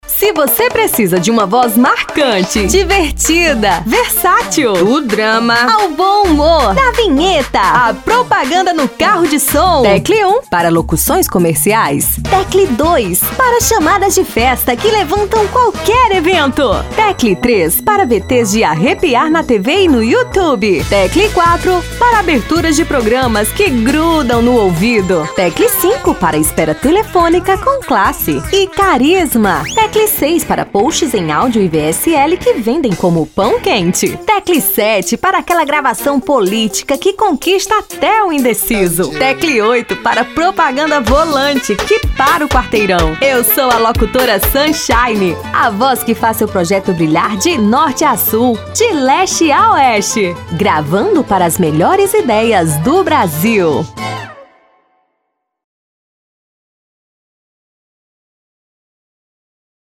Ceará